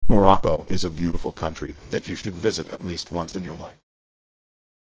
Voice cloning AI (deepfake for voice). Using cloned voice from only 5-10 seconds of targeted voice.